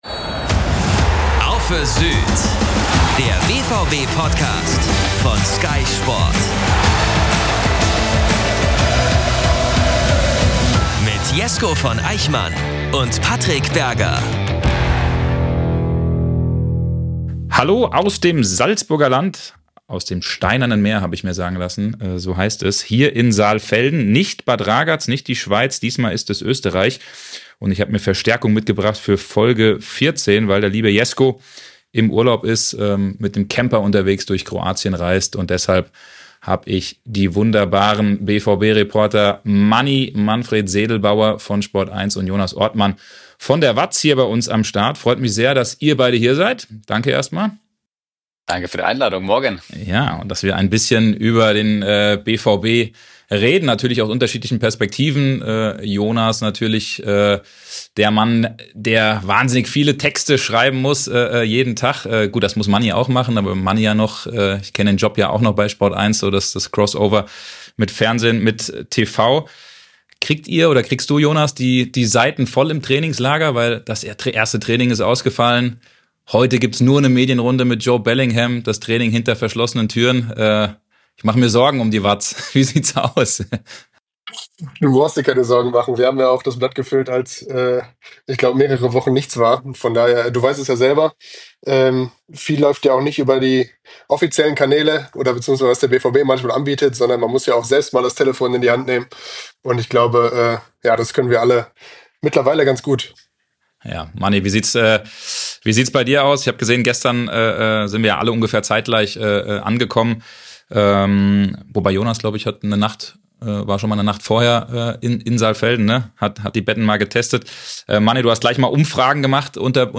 In dieser elitären Dreier-Runde wird unter anderem über das überraschende Angebot des BVB an Wolves-Stürmer Fabio Silva diskutiert.